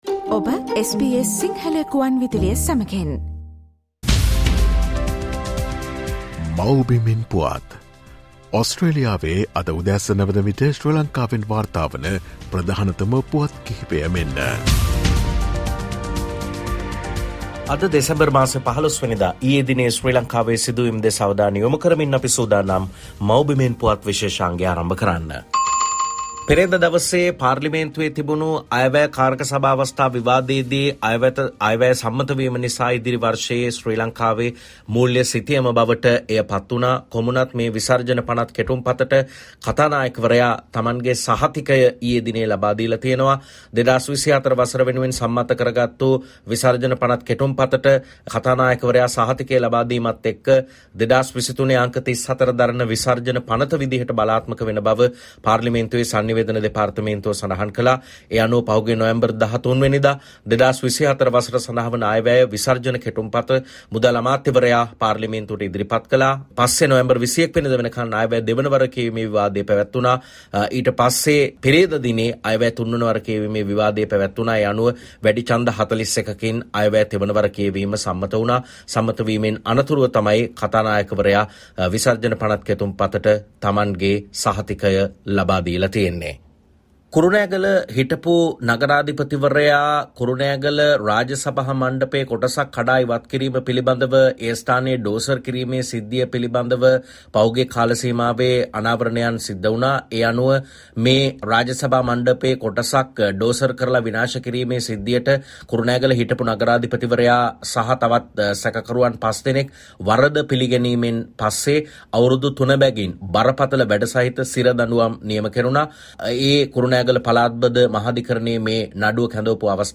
Here are the most prominent News Highlights of Sri Lanka.